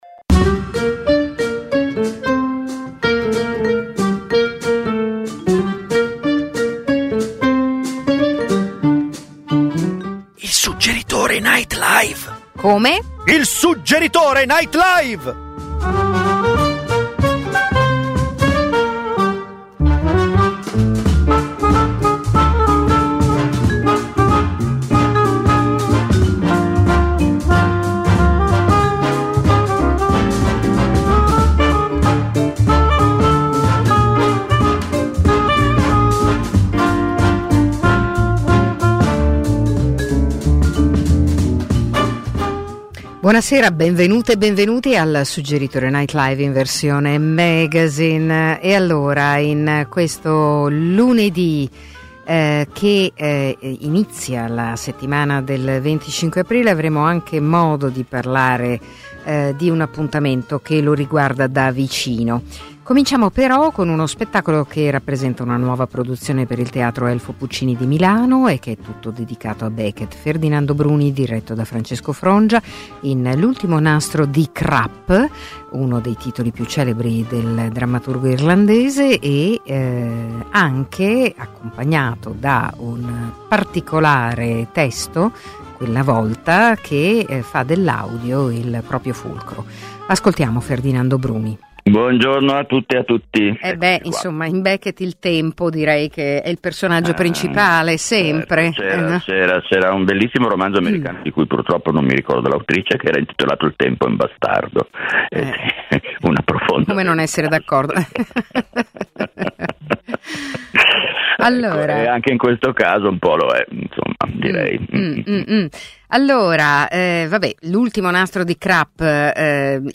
Il Suggeritore Night Live, ogni lunedì dalle 21:30 alle 22:30 dall’Auditorium Demetrio Stratos, è un night talk-show con ospiti dello spettacolo...
Gli ascoltatori possono partecipare come pubblico in studio a partire dalle 21.00. E spesso, il Suggeritore NL vi propone serate speciali di stand up, slam poetry, letture di drammaturgia contemporanea, imprò teatrale.